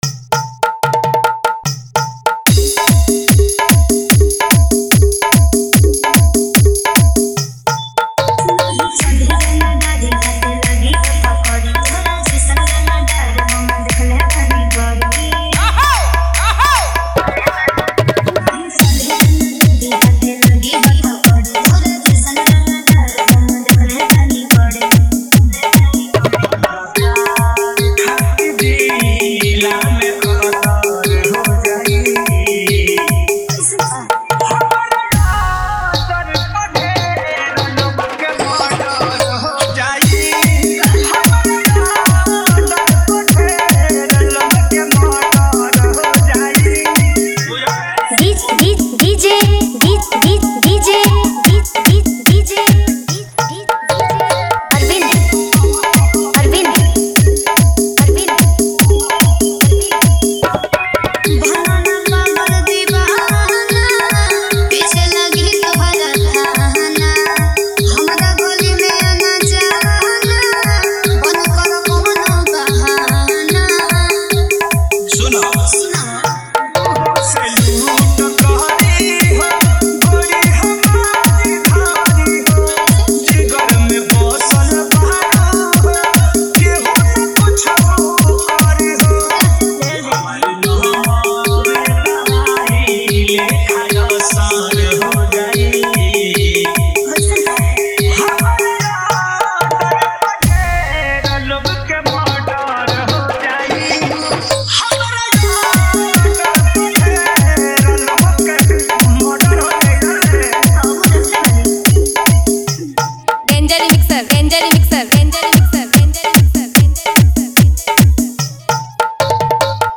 Category : dj remix songs bhojpuri 2025 new